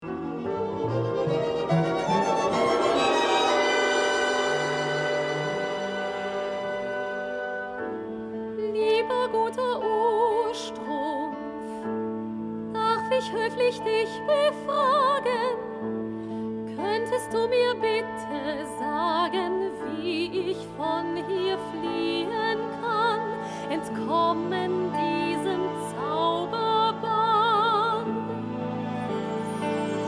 Piano & Vocal Score
Piano + Vocals